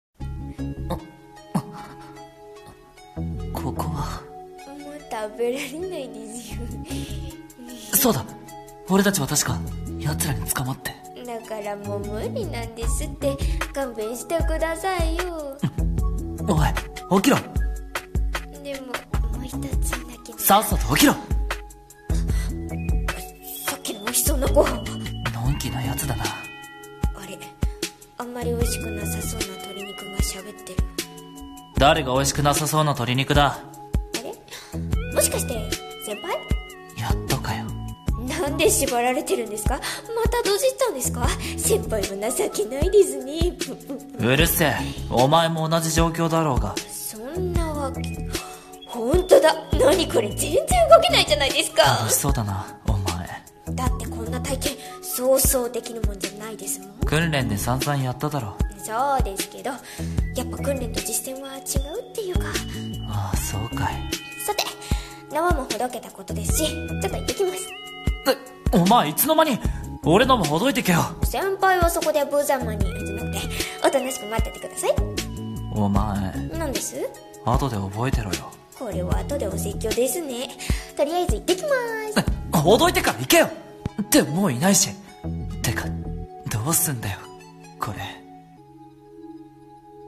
【どうすんだよコレ】声劇台本